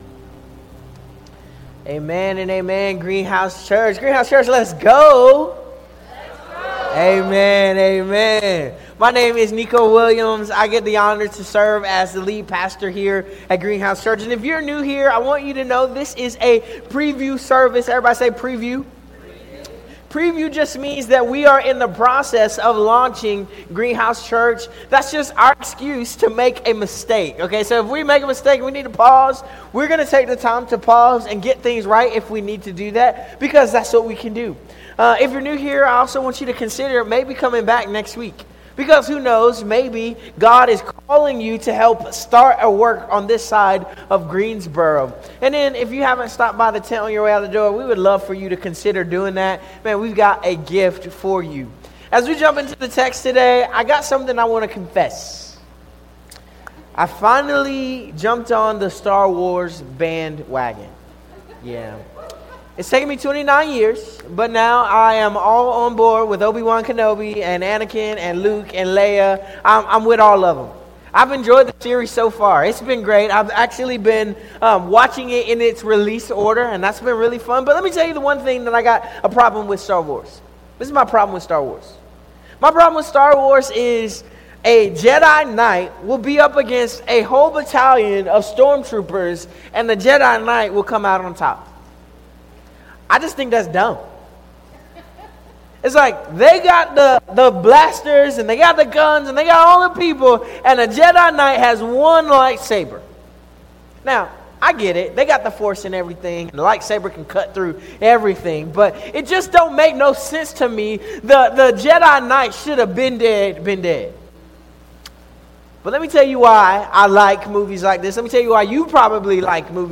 This sermon was delivered on June 22nd 2025 at Greenhouse Church.